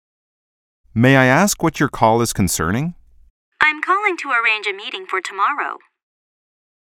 實境對話